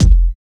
100 KICK 2.wav